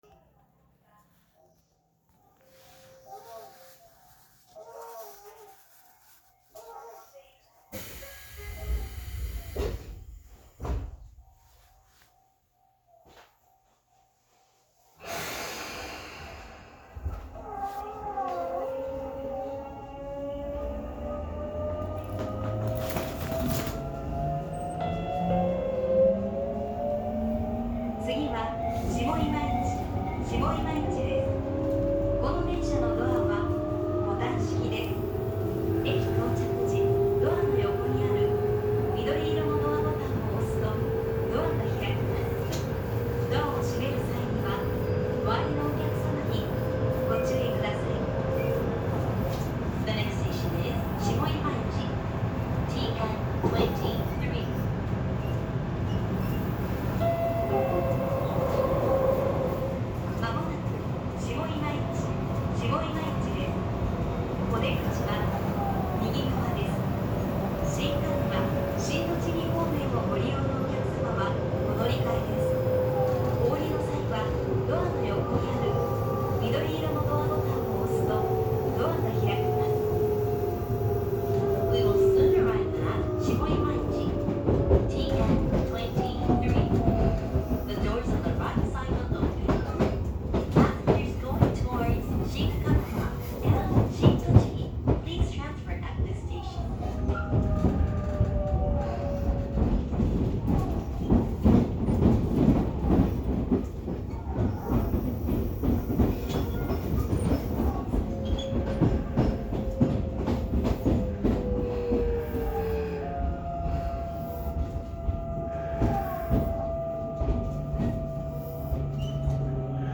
・20400系走行音
【日光線】上今市→下今市